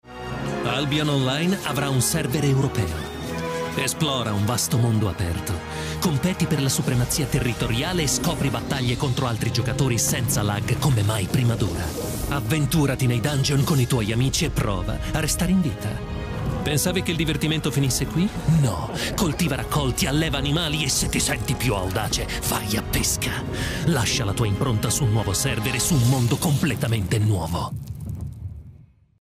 caratteri vari
estratti di cartoni animati e altro